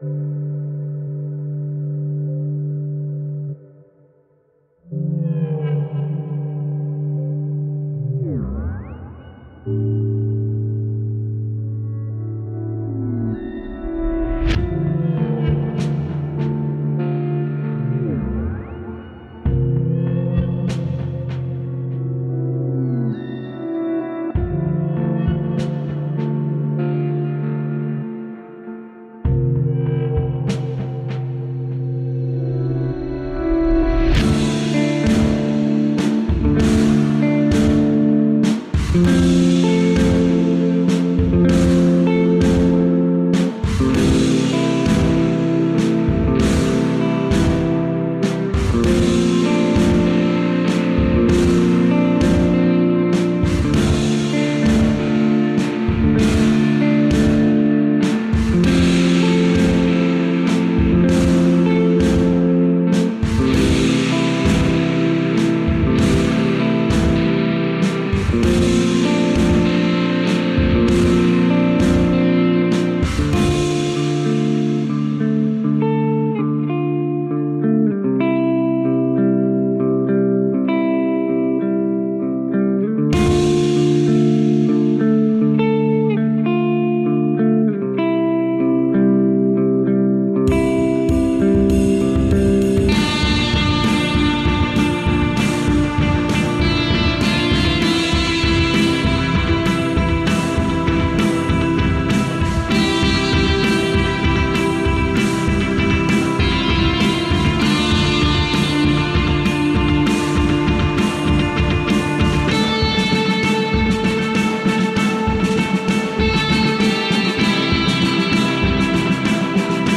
Ambient post-rock from the cold heart of siberia.
Tagged as: Alt Rock, Experimental, Prog Rock